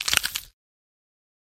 Звуки салата
Звук зубами откусанный стебель салата